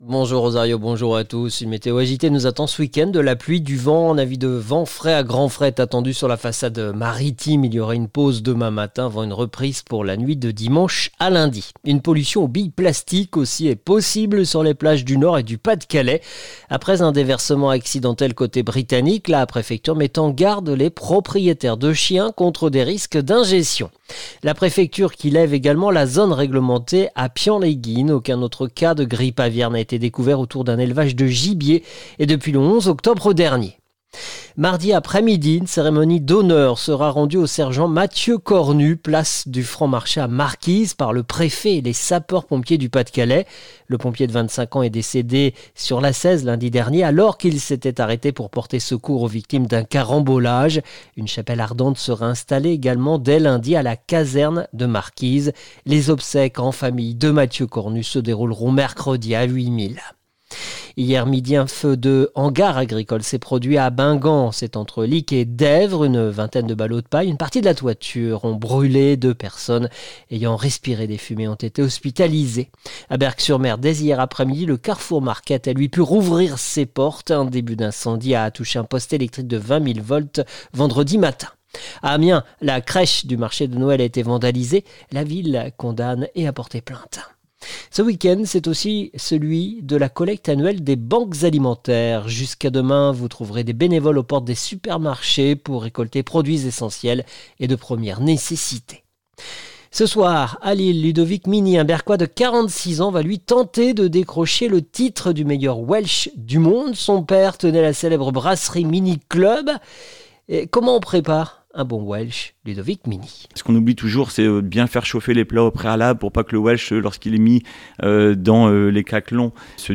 Le journal du samedi 29 novembre 2025